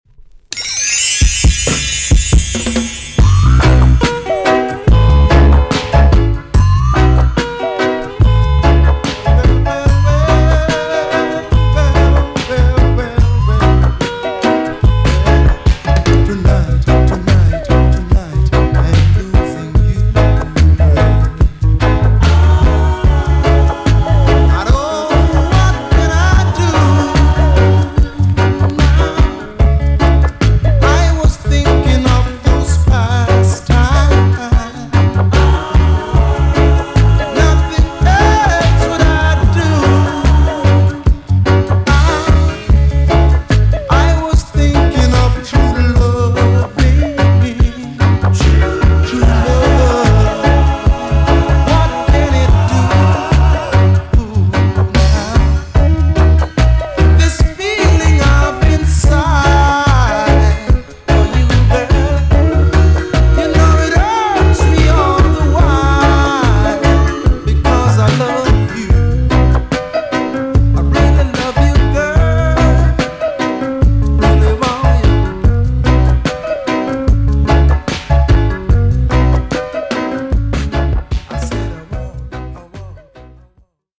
EX- 音はキレイです。
NICE LOVERS ROCK TUNE!!